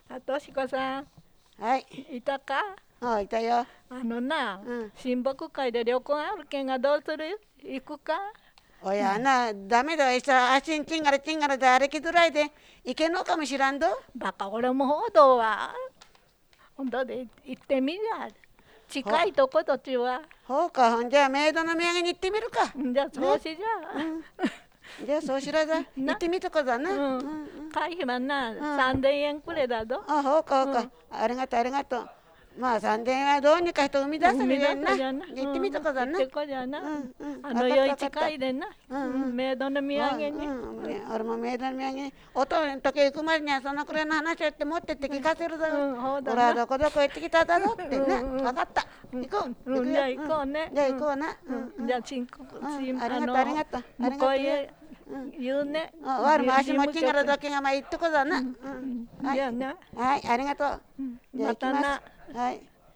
会話（ロールプレイ） ─茂倉─
[6] 末尾の「ぞ」： [ðo] か。「ど」[do]に近く聞こえる。
[9] 「きたーだぞ」の「ぞ」：[ðo] か。「ど」[do] に近く聞こえる。
[10] むこーえ：[mɯkoːje] 渡り音[j]がはっきり聞こえる。